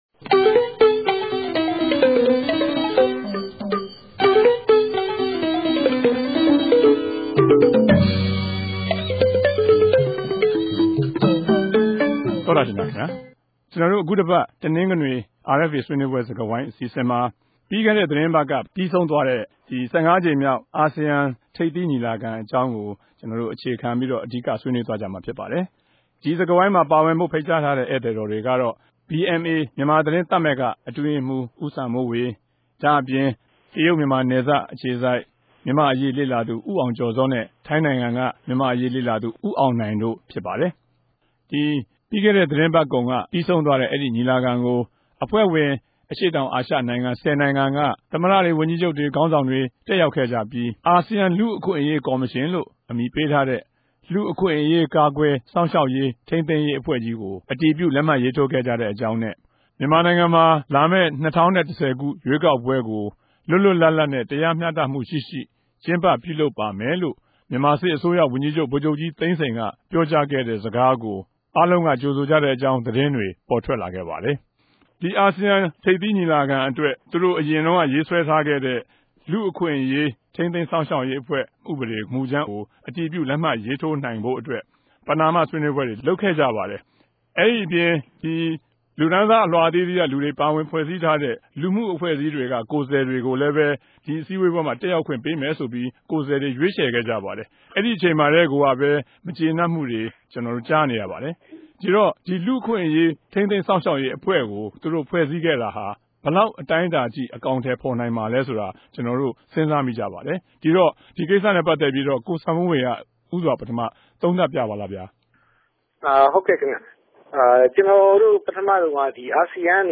အခုတပတ် တနဂဿေိံြ ဆြေးေိံြးပြဲစကားဝိုင်းမြာ မုကာသေးခင်က ္ဘပီးဆုံးသြားတဲ့ အရြေႛတောင်အာရြ ိံိုင်ငံမဵားအဖြဲႛ ထိပ်သီးညီလာခံက ပေၞထြက်ခဲ့တဲ့ အေူခအနေတေနြဲႛ ူမန်မာိံိုင်ငံအပေၞ အကဵိြးသက်ရောက်မြတေကြို ဆြေးေိံြးထားုကပၝတယ်။